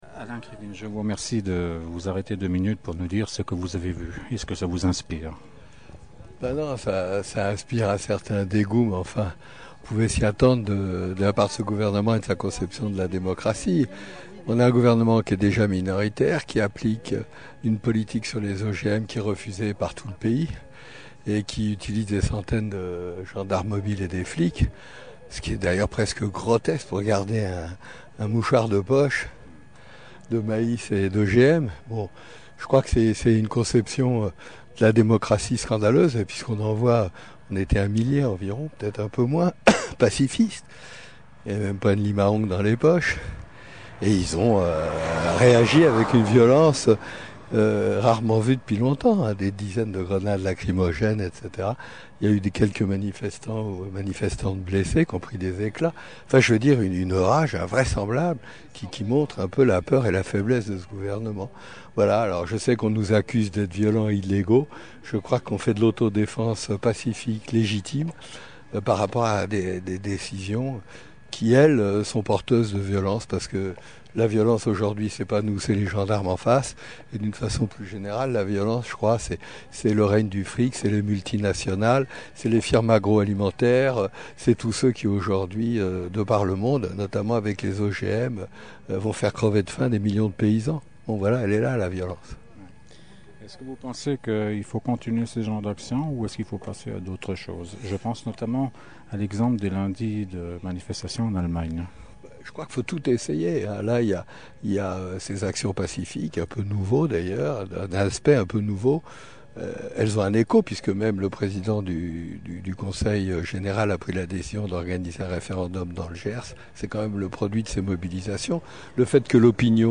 L’entretien audio (au format mp3)